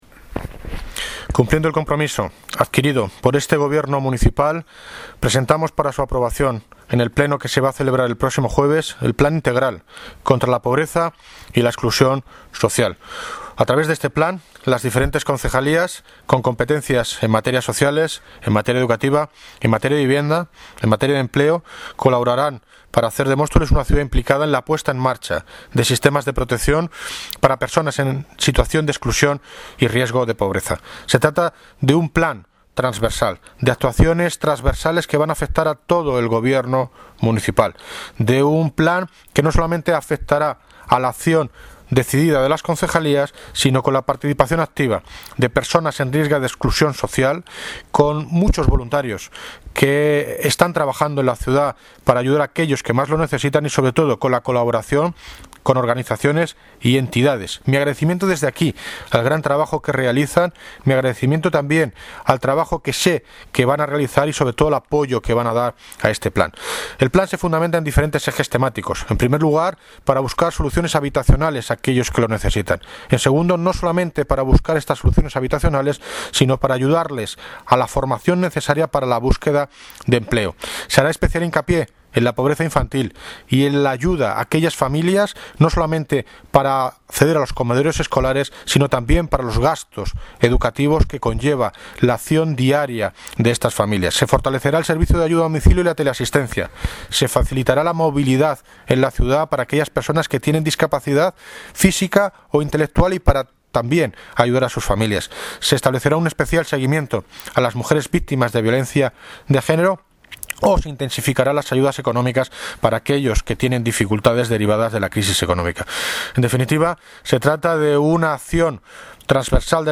Audio - David Lucas (Alcalde de Móstoles) Sobre Plan Integral contra la pobreza y la exclusión social